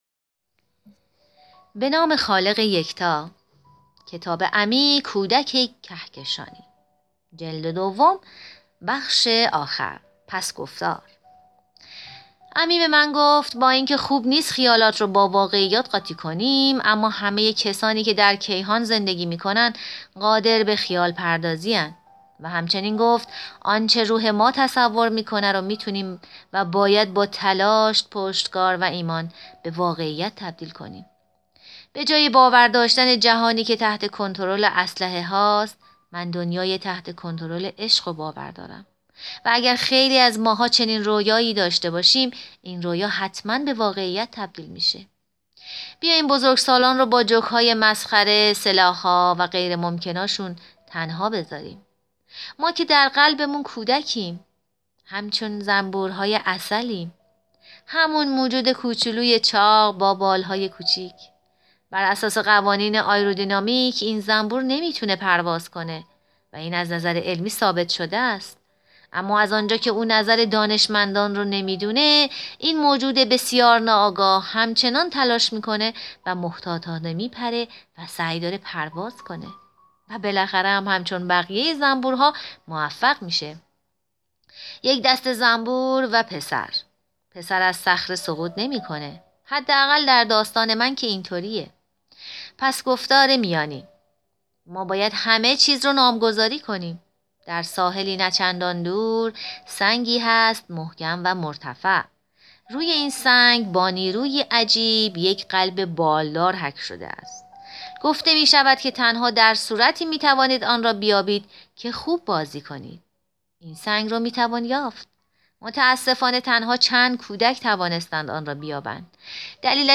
عضو کمیته استیناف: محرومیت لیگ ربطی به جام حذفی ندارد گل سوم و زیبای روبرت لواندوفسکی به سلتاویگو / فیلم برچسب‌ها: کتاب گویای امی کودک کهکشانی امی کودک کهکشانی دیدگاه‌ها (اولین دیدگاه را بنویسید) برای ارسال دیدگاه وارد شوید.